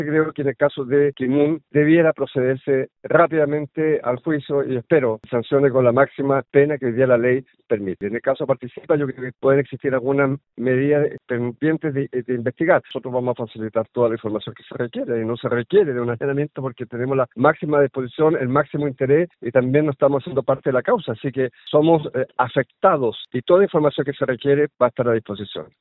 Gobernador Alejandro Santana por arista Kimün en Caso Convenios